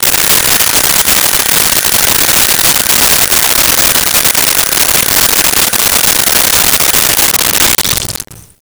Sink Fill 06
Sink Fill 06.wav